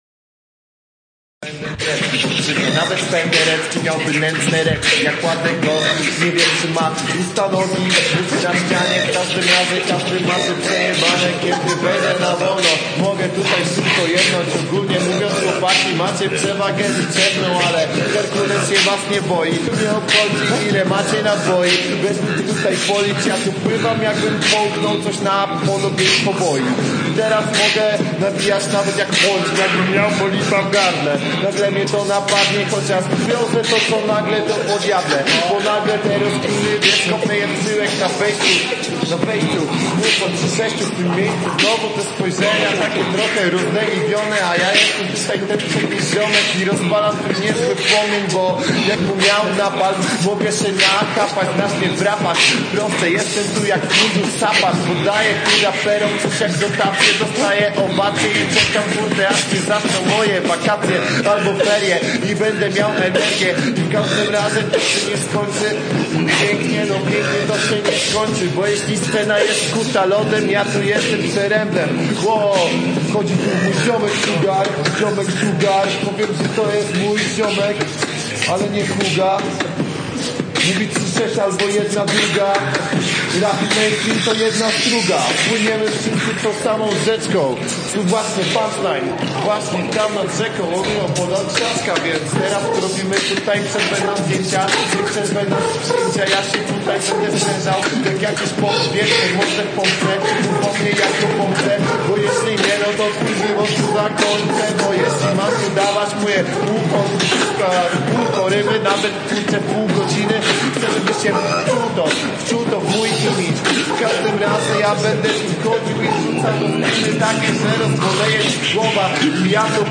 Plik maksymalnie skompresowany, ale słychać conieco.